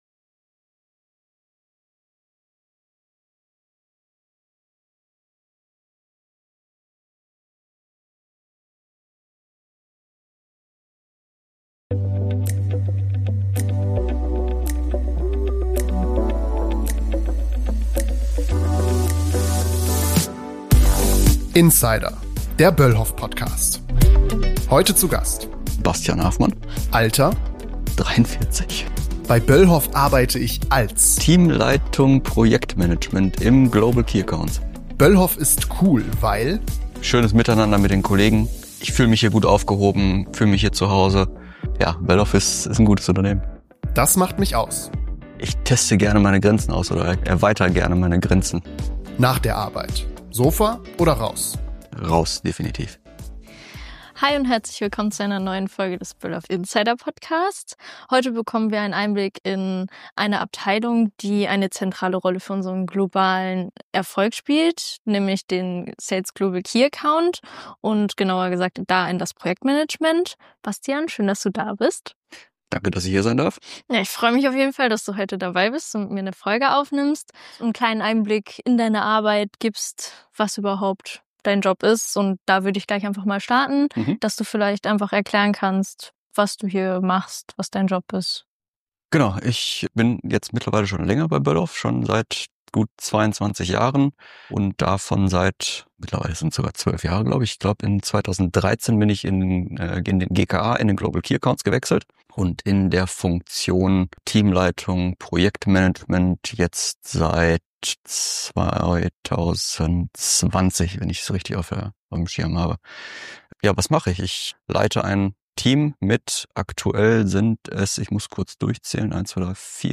Ein Gespräch über Teamwork, Ausdauer und echte Leidenschaft – im Job und auf dem Sattel.